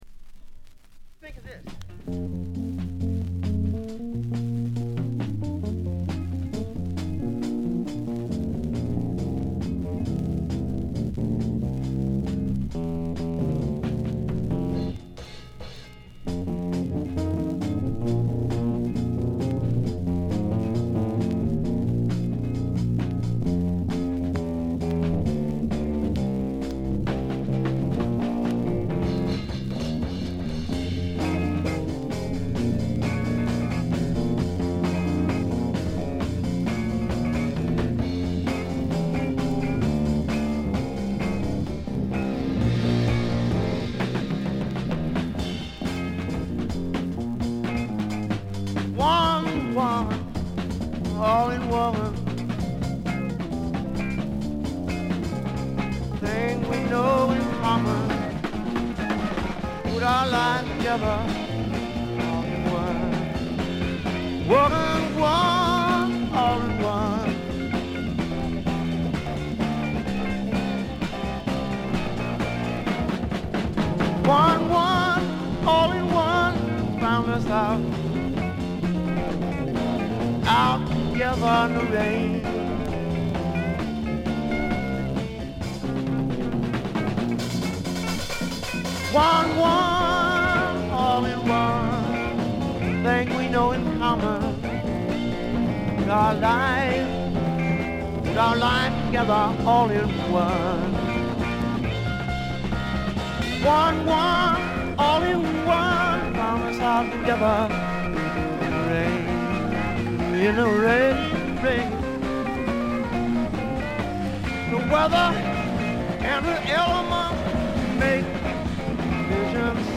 バックグラウンドノイズ、チリプチ多め大きめですが凶悪なものや周回ノイズはありません。
試聴曲は現品からの取り込み音源です。
Guitar